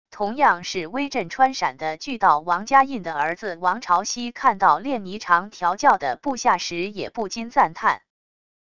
同样是威震川陕的巨盗王嘉胤的儿子王朝希看到练霓裳调教的部下时也不禁赞叹wav音频生成系统WAV Audio Player